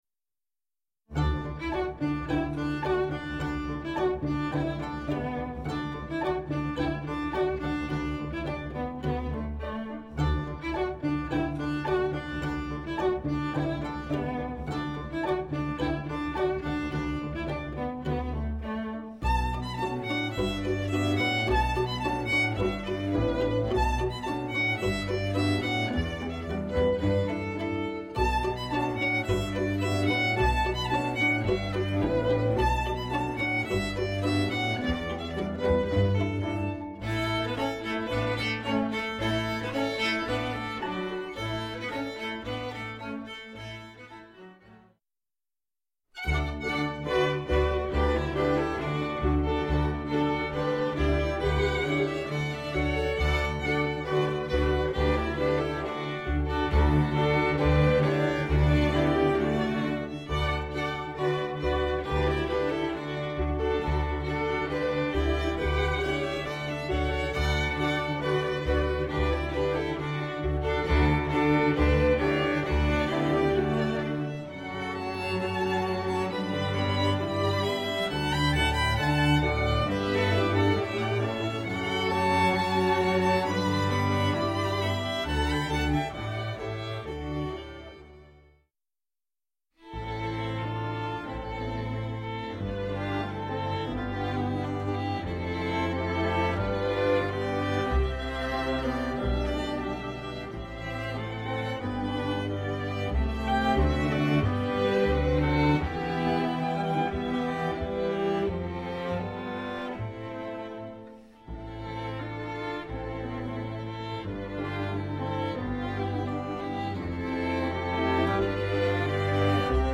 Orchestre à Cordes